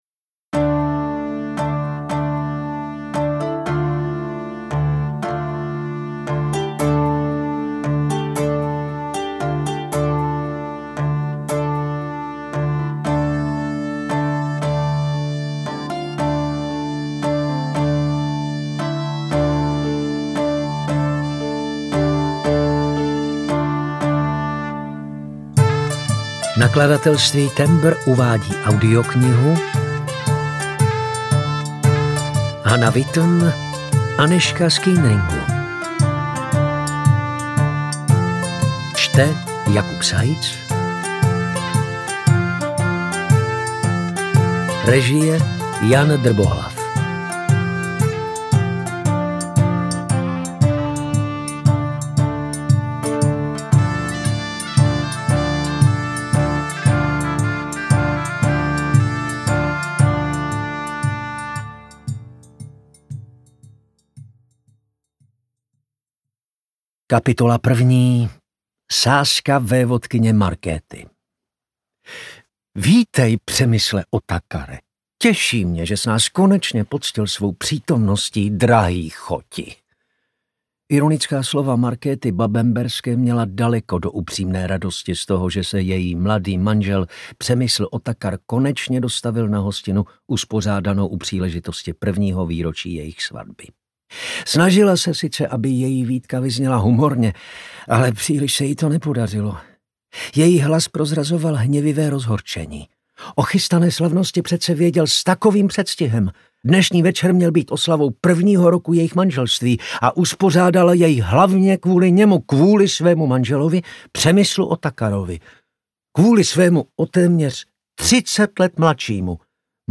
UKÁZKA Z KNIHY
audiokniha_anezka_z_kuenringu_ukazka.mp3